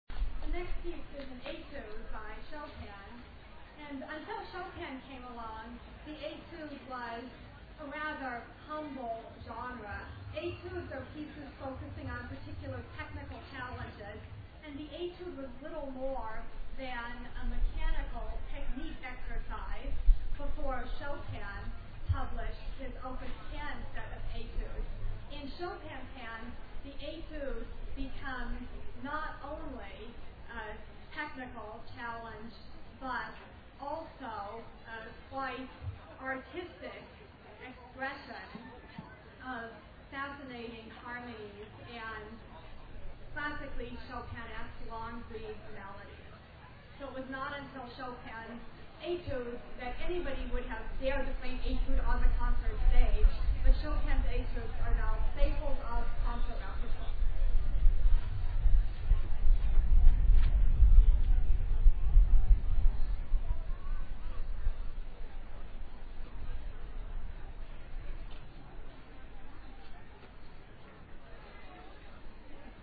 Classical Music Festival
Part of the Arts and Wine Festival
Courthouse Park, Cortland, NY USA